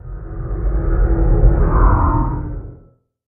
Minecraft Version Minecraft Version latest Latest Release | Latest Snapshot latest / assets / minecraft / sounds / mob / guardian / elder_idle4.ogg Compare With Compare With Latest Release | Latest Snapshot
elder_idle4.ogg